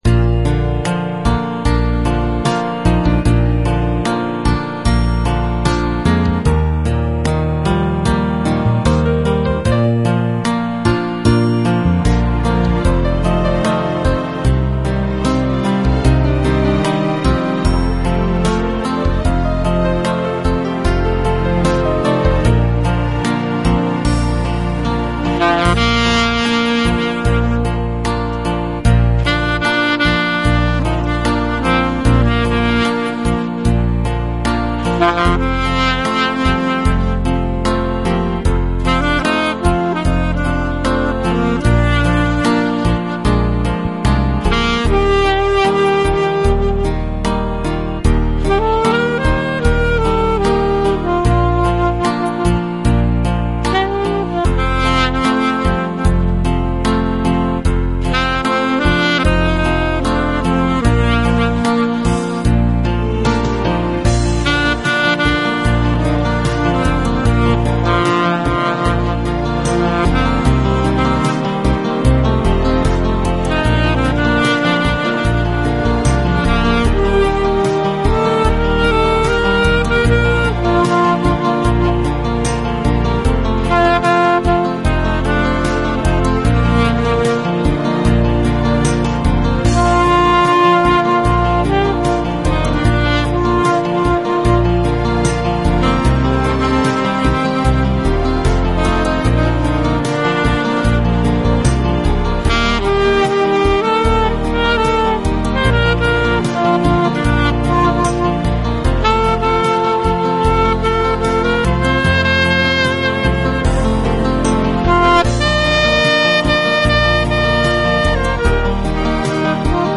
0 항상 녹음을 하고 보면 박자를 놓치는 부분이...